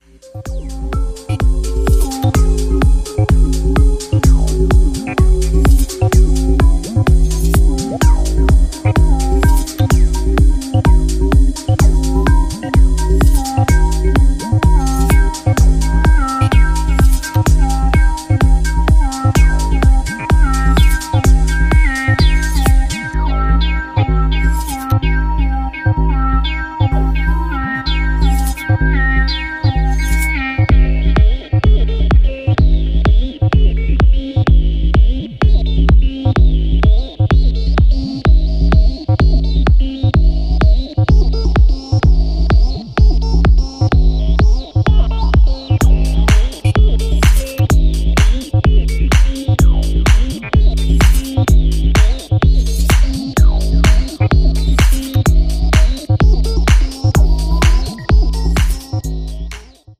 ディープな効果を追求したアシッド工業的デザインの洗練さも極まった匠のトラックがズラリ。